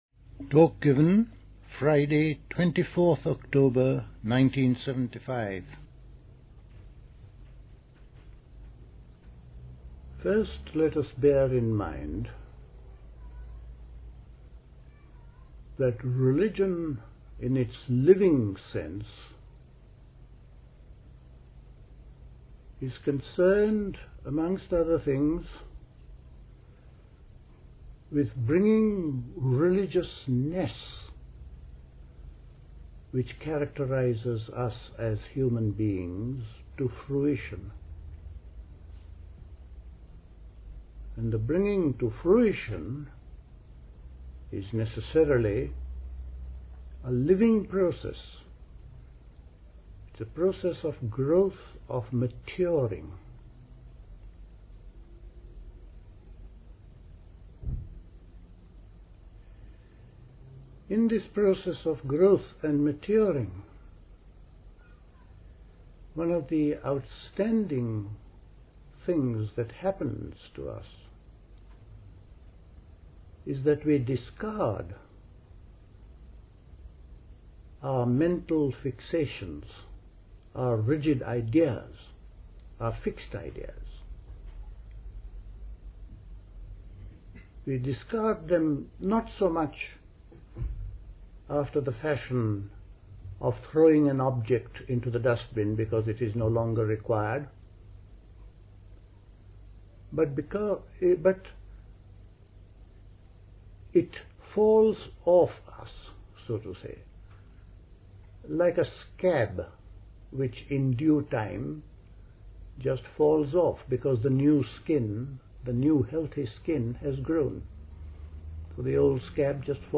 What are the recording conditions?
at Dilkusha, Forest Hill, London